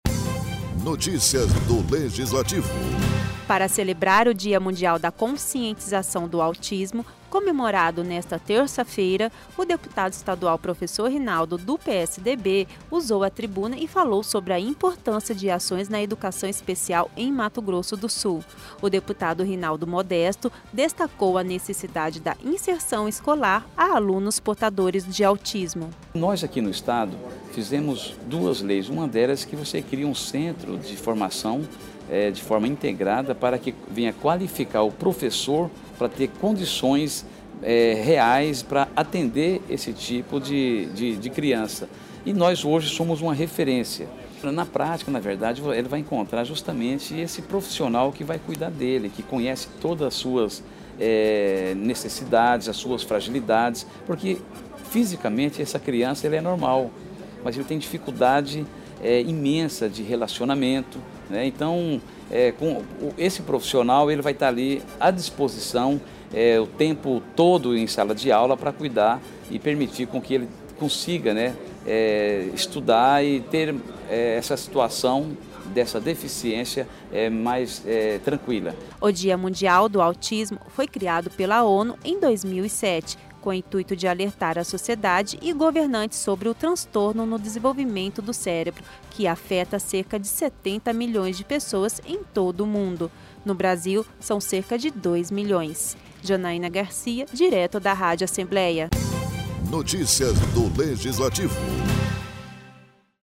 O deputado estadual Professor Rinaldo (PSDB), usou a tribuna nesta terça-feira na Assembleia Legislativa, para falar sobre a importância do Dia Mundial de Conscientização sobre o Autismo.